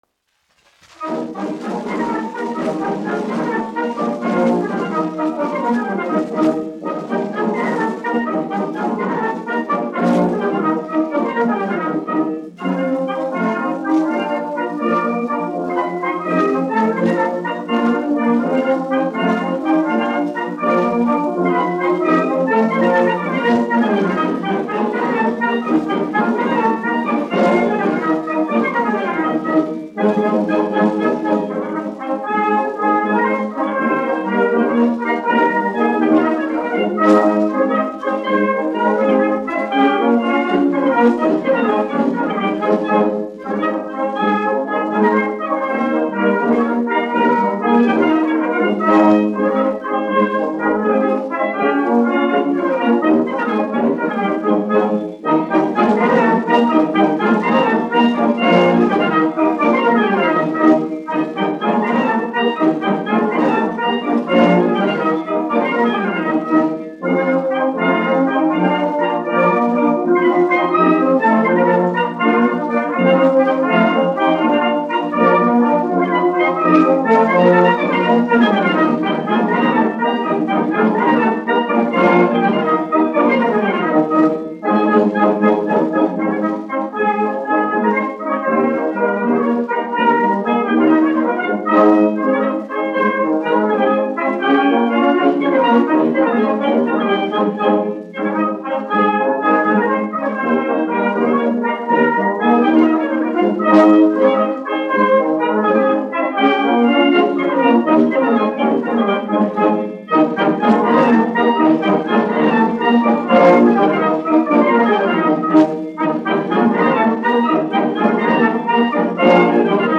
1 skpl. : analogs, 78 apgr/min, mono ; 25 cm
Pūtēju orķestra mūzika
Mazurkas
Latvijas vēsturiskie šellaka skaņuplašu ieraksti (Kolekcija)